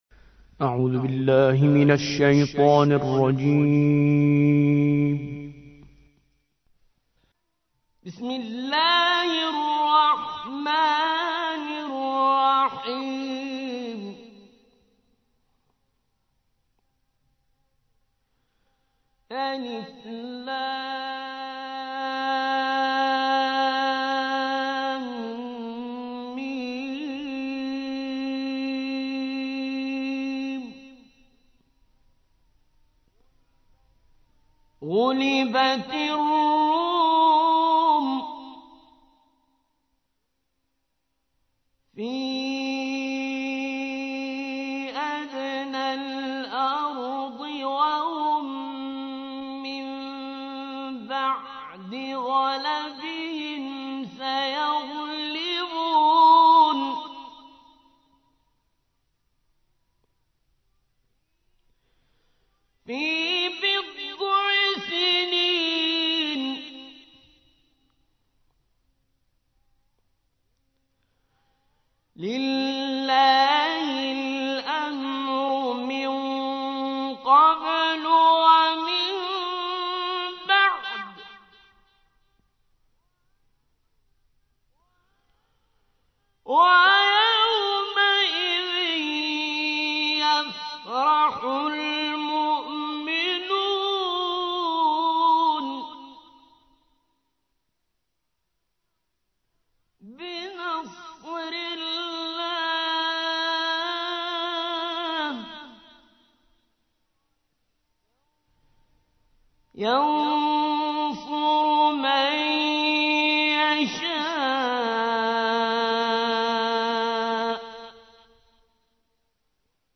30. سورة الروم / القارئ